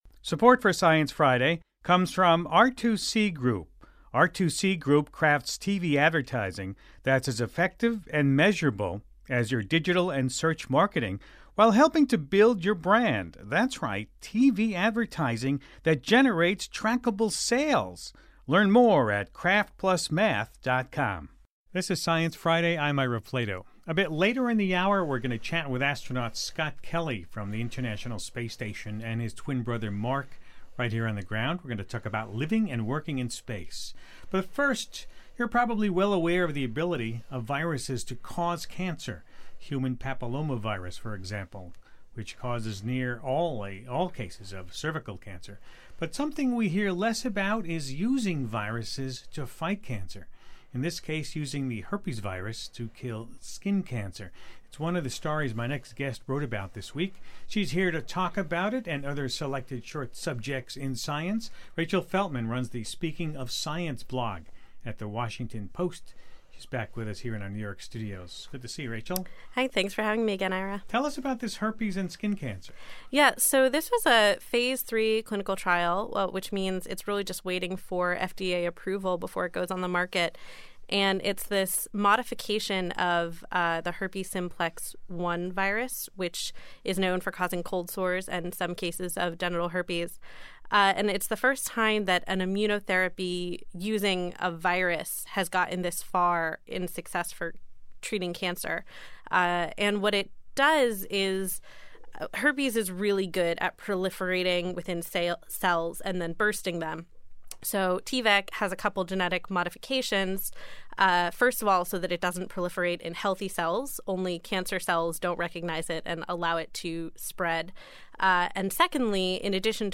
A roundup of the top science stories this week, a fossil jaw of a possible new hominin species, and a conversation with two twin astronauts, one on board the ISS, one on Earth.